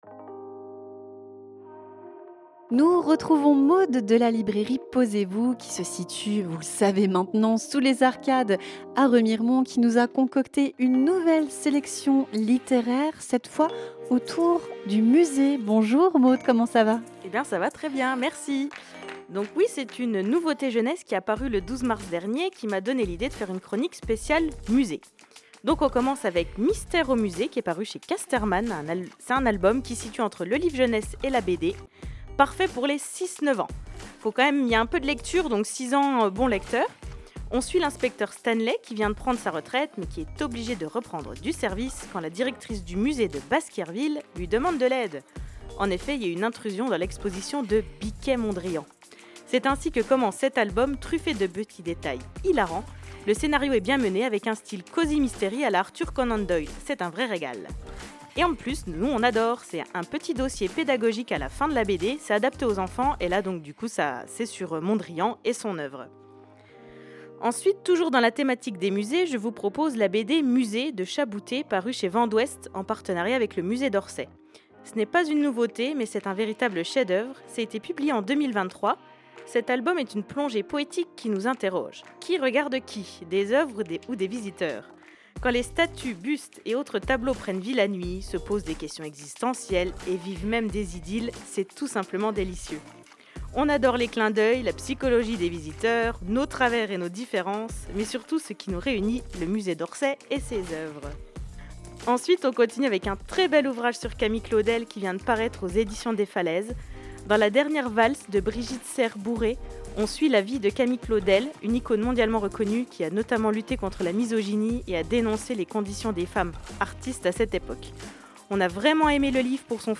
Pour cette nouvelle chronique littéraire